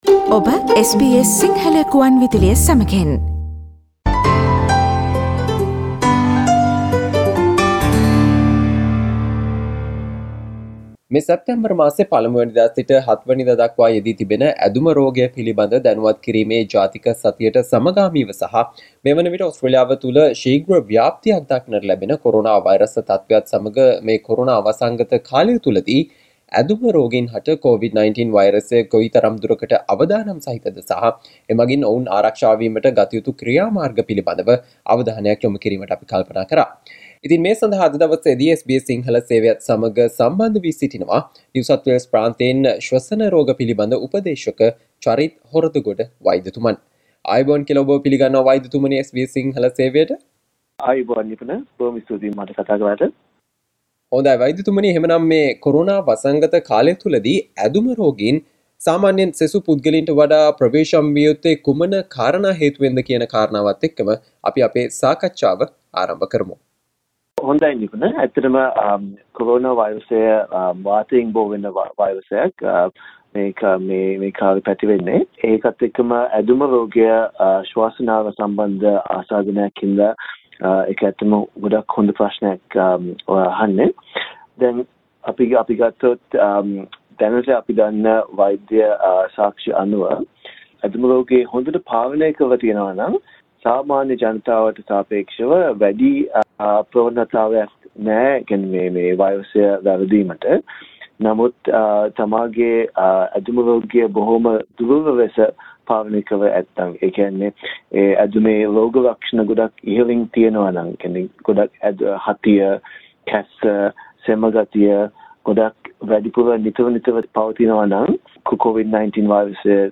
සැප්තැම්බර් මස 1 - 7 කාලයට යෙදී තිබෙන ඇදුම රෝගය පිලිබඳ දැනුවත් කිරීමේ ජාතික සතියට සමගාමීව මේ කොරෝනා වසංගත කාලය තුලදී ඇදුම රෝගීන් හට COVID-19 වෛරසය කෙතරම් දුරකට අවදානම් ද සහ එමගින් ඔවුන් ආරක්ෂා වීමට ගතයුතු ක්‍රියාමාර්ග පිළිබඳව SBS සිංහල සේවය සිදු කල සාකච්චාවට සවන්දෙන්න